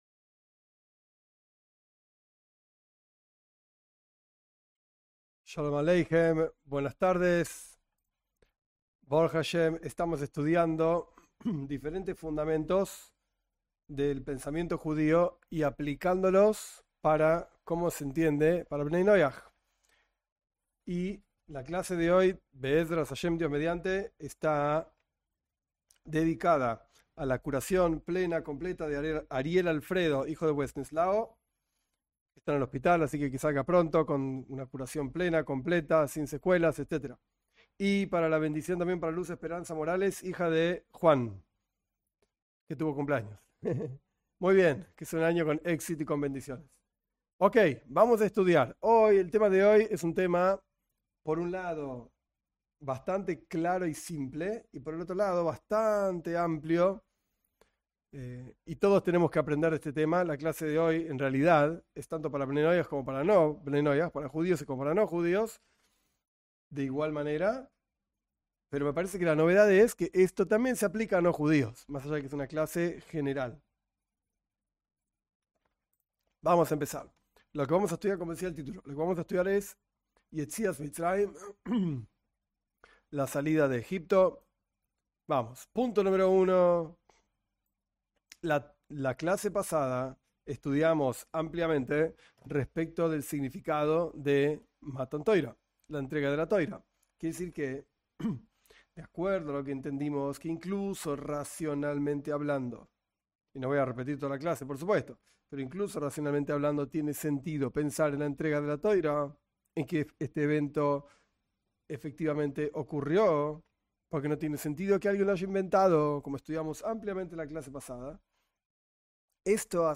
Esta es una serie de clases basada en unos videos cortos sobre asuntos de Bnei Noaj.
Cada clase tiene un tiempo de preguntas que los participantes preparan de antemano.